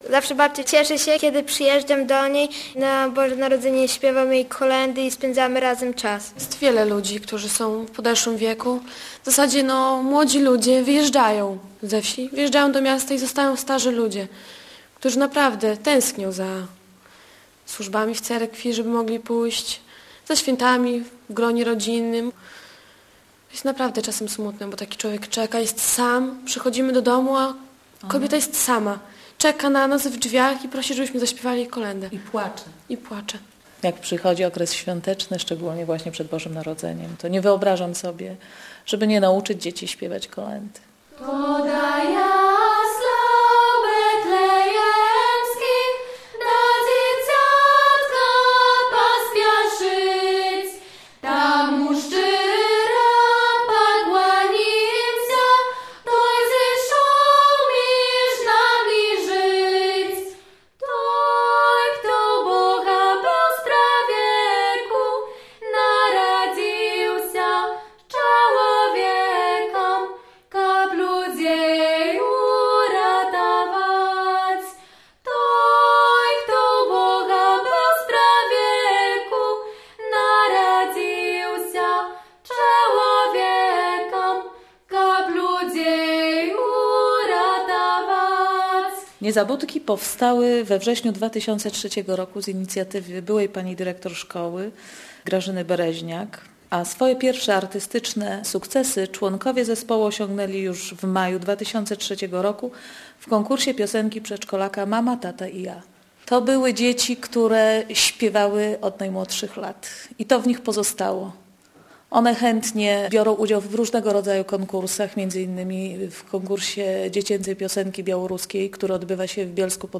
Radio Białystok | Reportaż | "Z kolędą idziemy..."